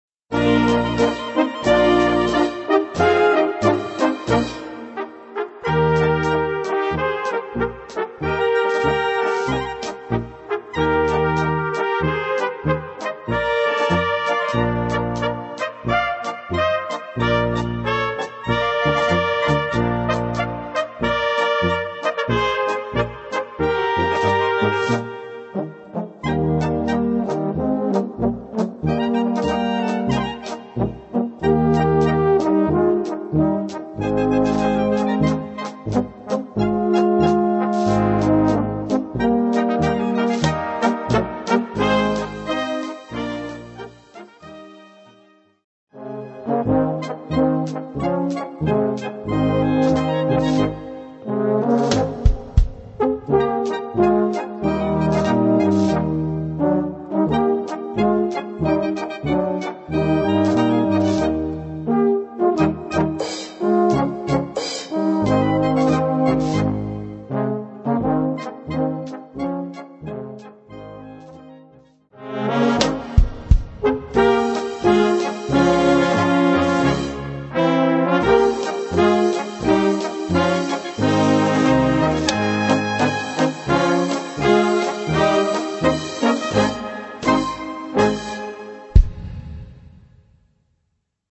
Gattung: Polka
A4 Besetzung: Blasorchester Zu hören auf